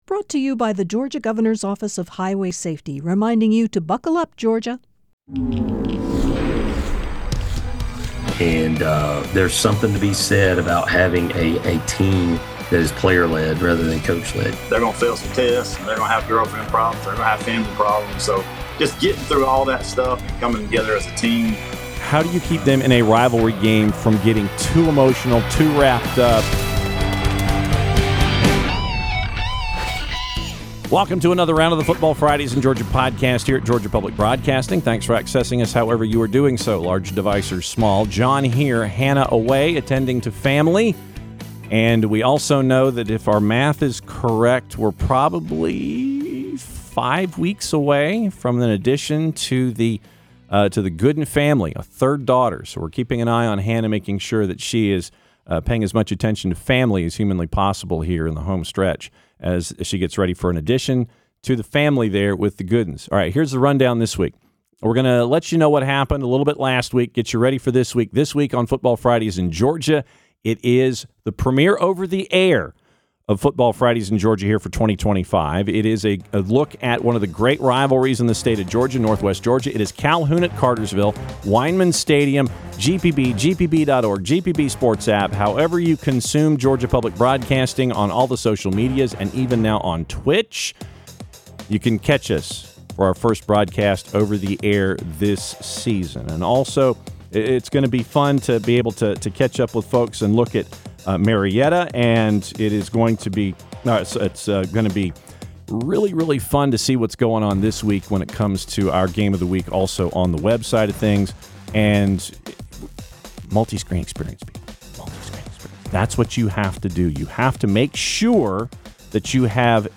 In this episode of the Football Fridays in Georgia podcast, you explore two of the state’s most legendary high school football rivalries: Calhoun vs. Cartersville and Thomasville vs. Thomas County Central. Coaches share what makes these matchups unforgettable, from the history and tradition to the pride each community brings to Friday night lights.